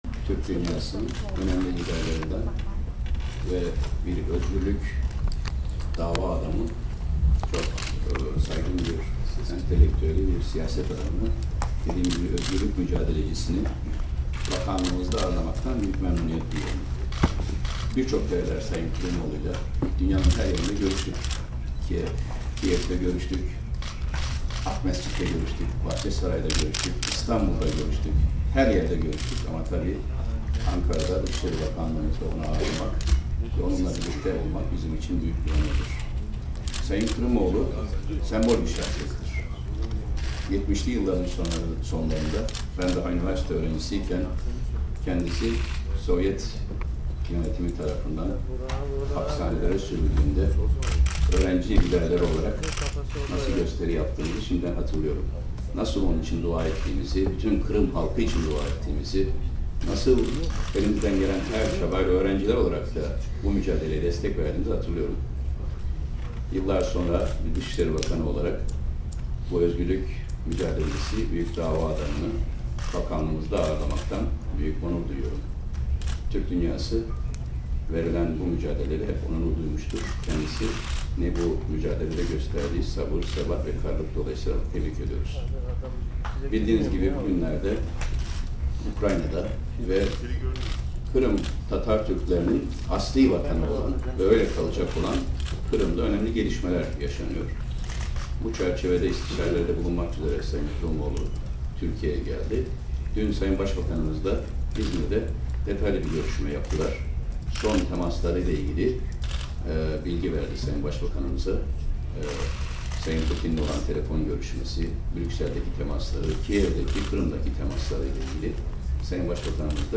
Davutoğlu ve Kırımoğlu Basın Toplantısı